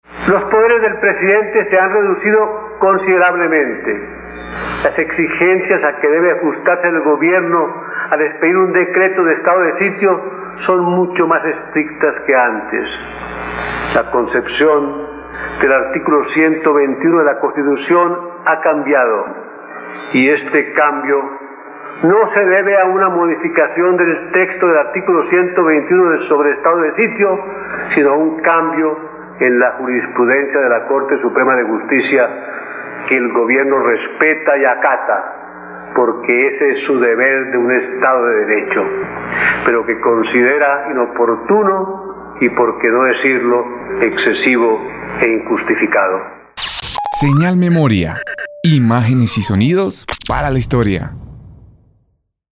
Virgilio Barco Vargas - Alocución presidencial (Ene - 1988) Audio Señal Memoria.